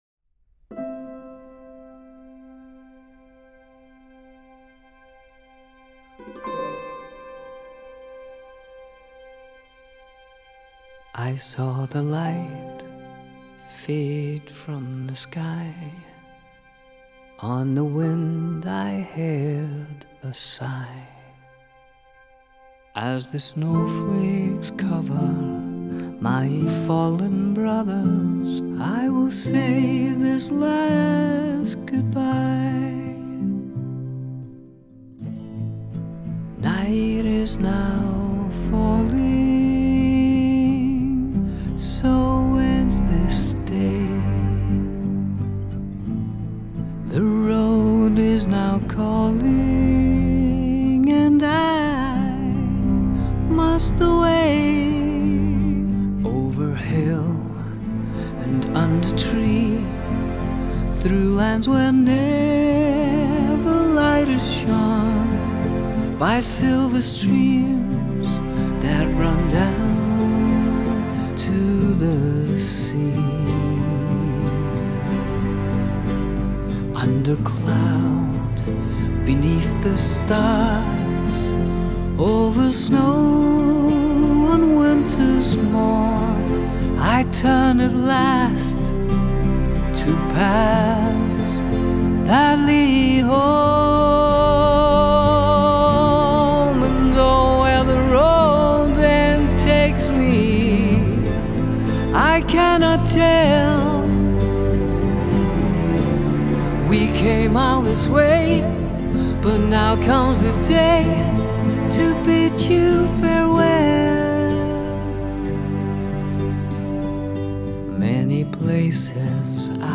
work his lovely singing pipes again.